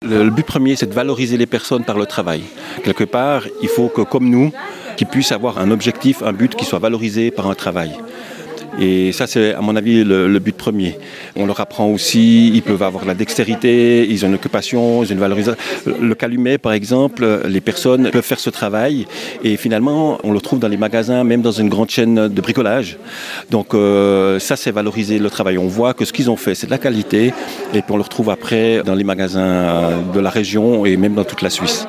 Interview 1